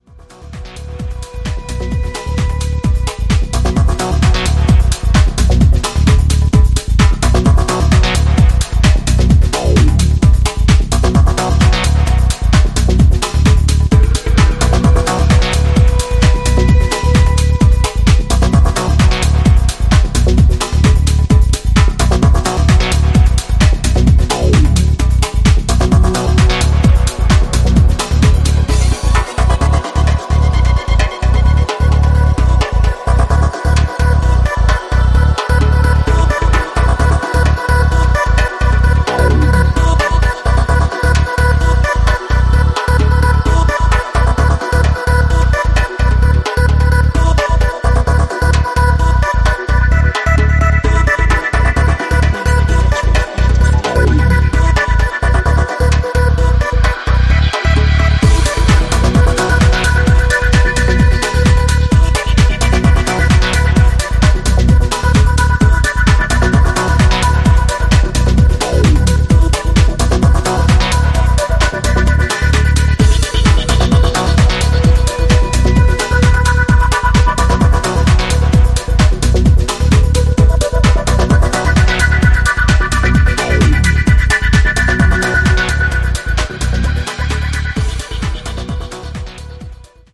90sプログレッシヴ・ハウス/トランスの影響を昇華した極彩色の楽曲群を展開